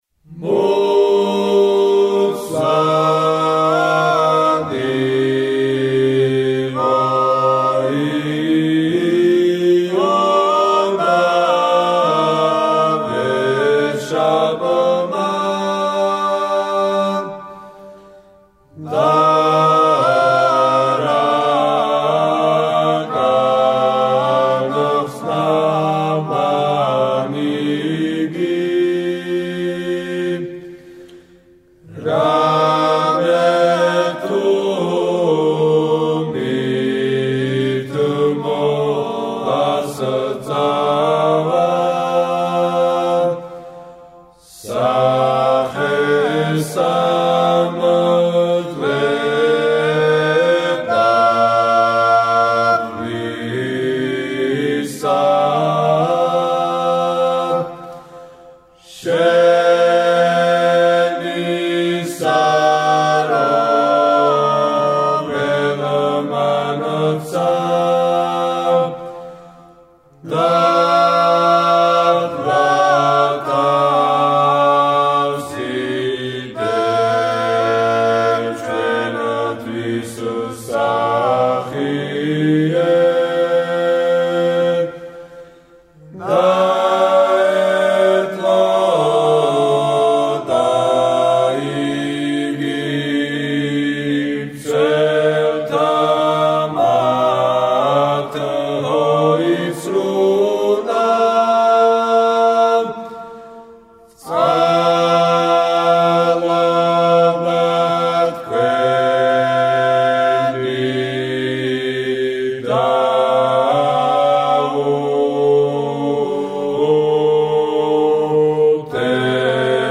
საგალობელი